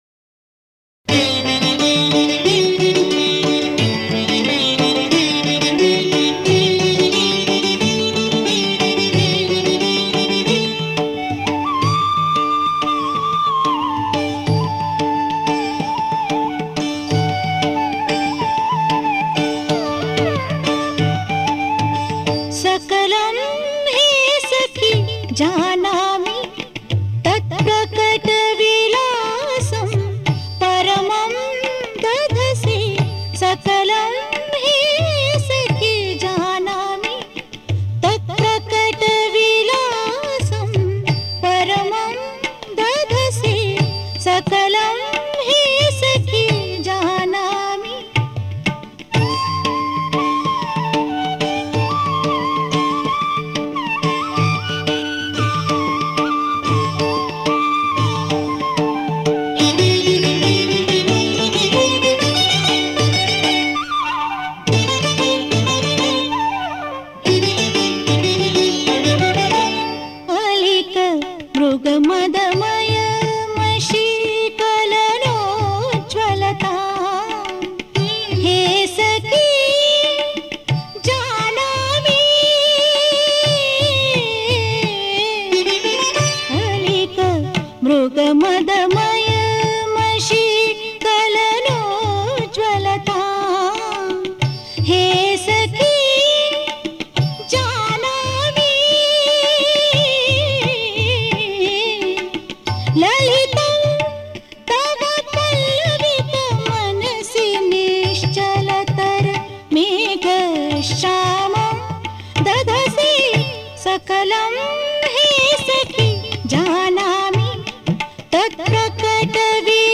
సంగీతం
సంకీర్తన